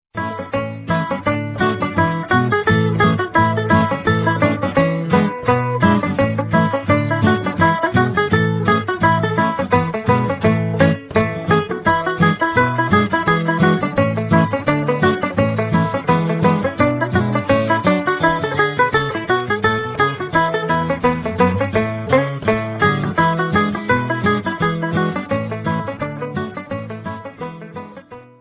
fiddle
melodeon